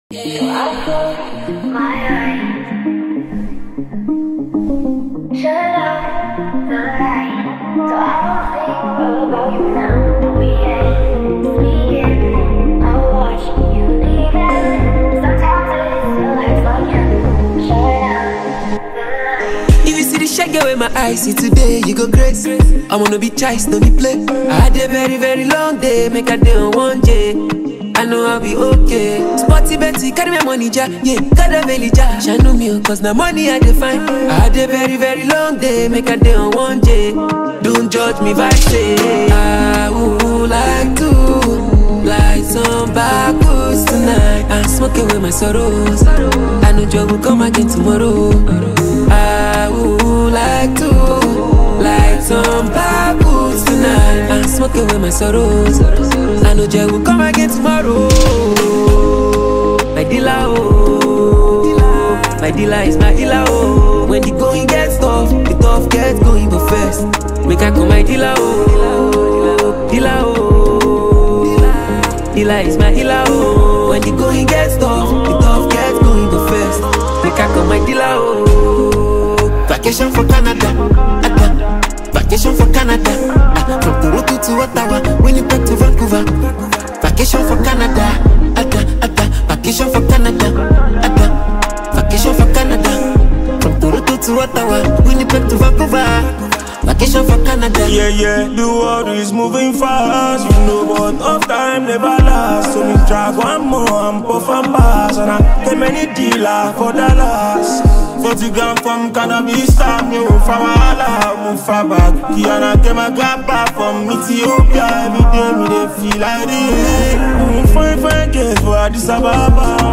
heartfelt delivery